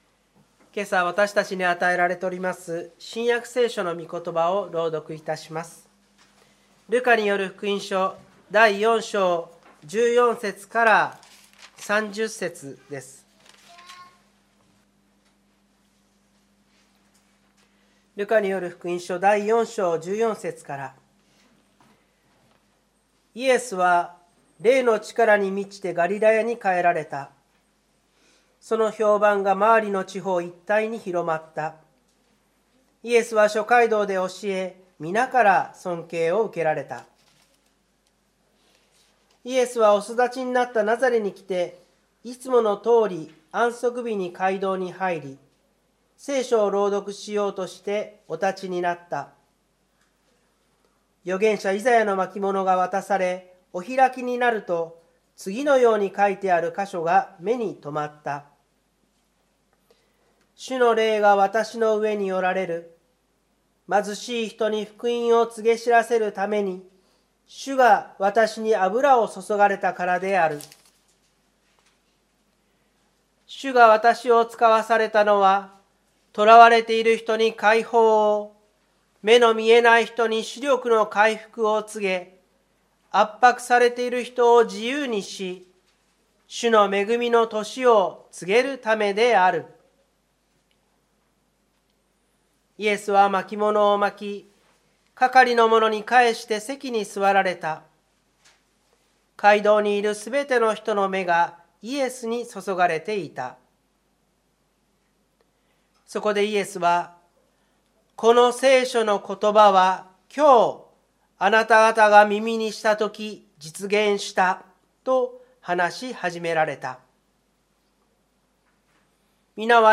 湖北台教会の礼拝説教アーカイブ。
日曜朝の礼拝